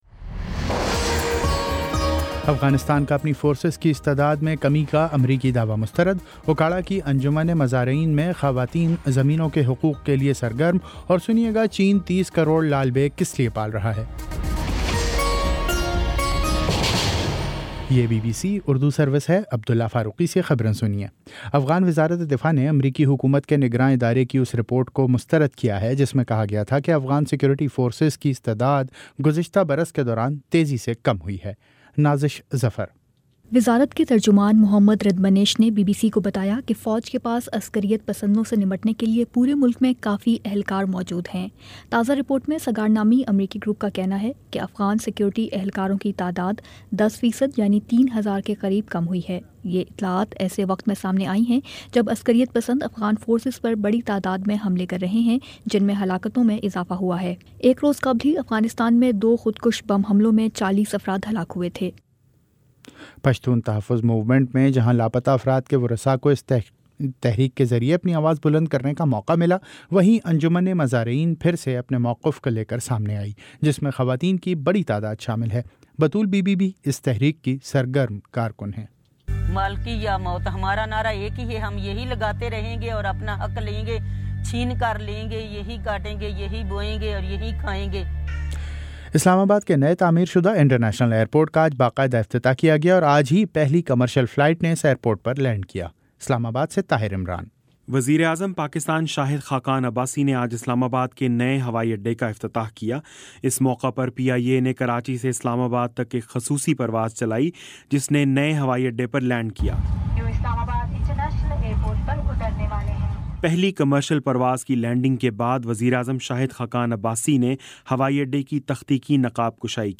مئی 01 : شام سات بجے کا نیوز بُلیٹن